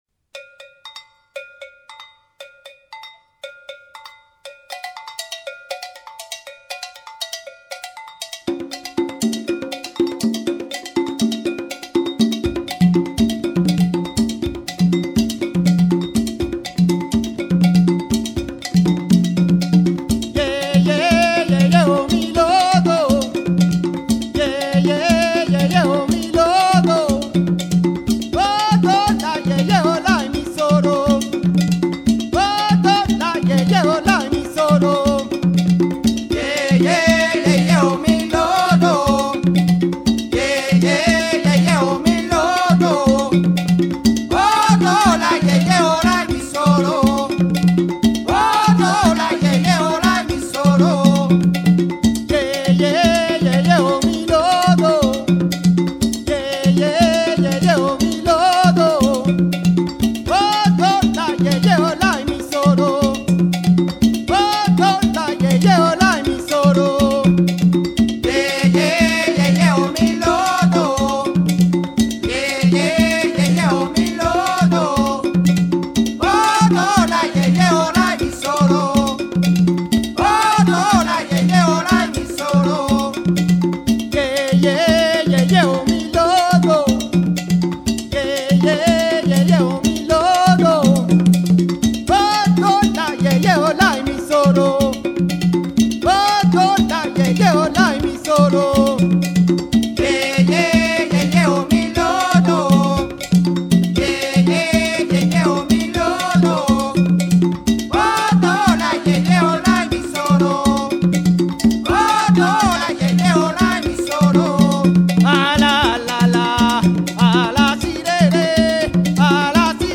Percussion at school
coro, catchimbo and mula
coro and agogo
caja
lead vocal